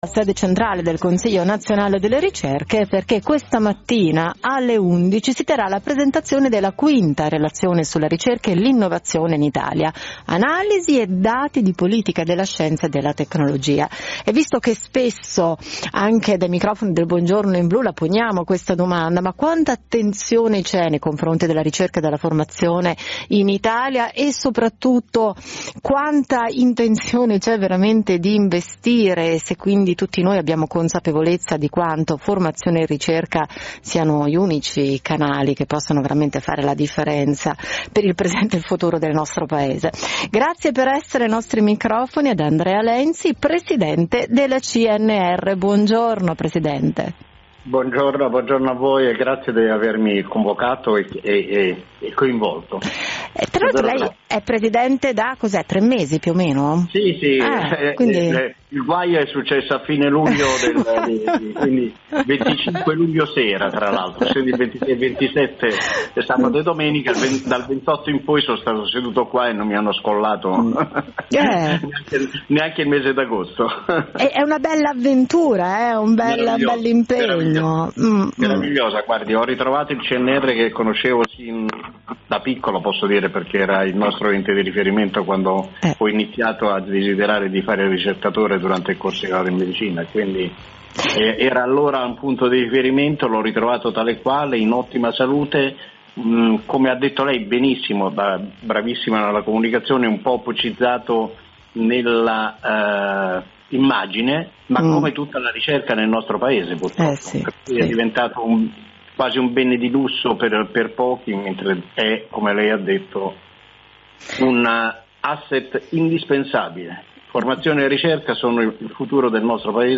Intervista: Acquisizione di Chrysler da parte di FIAT (mp3 file)